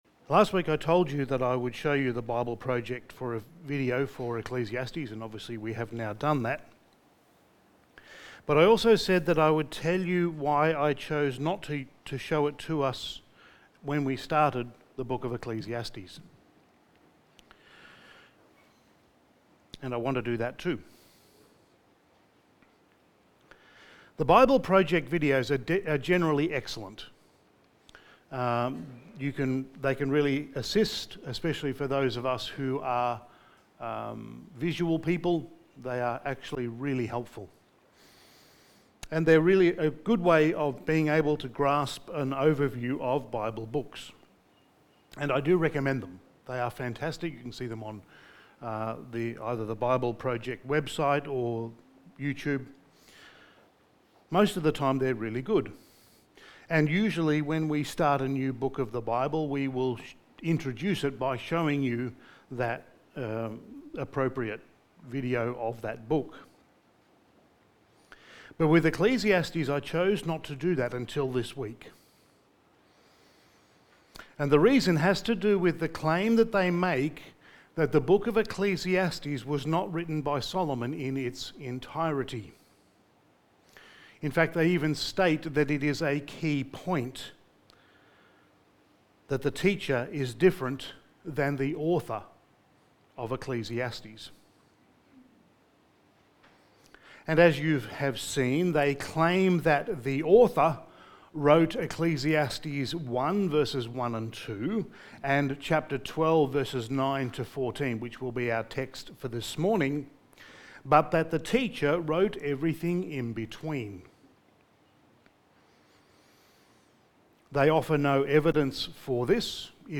Passage: Ecclesiastes 12:9-14 Service Type: Sunday Morning